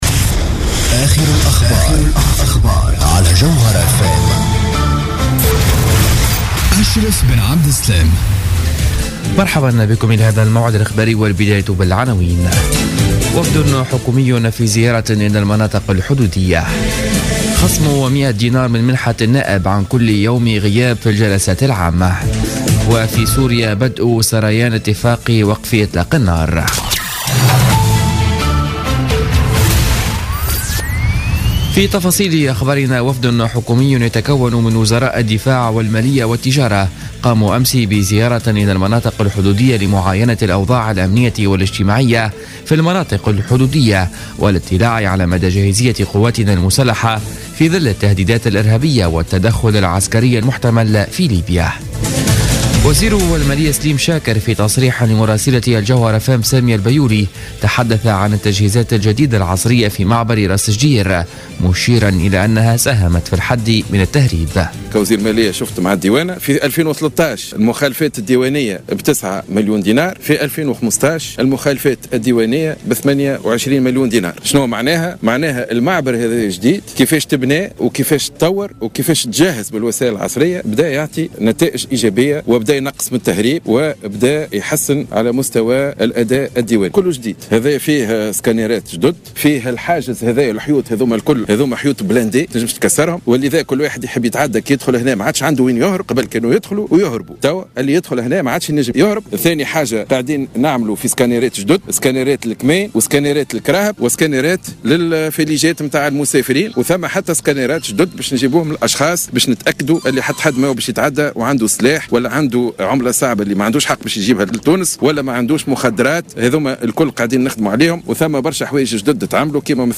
نشرة أخبار منتصف الليل ليوم السبت 27 فيفري 2016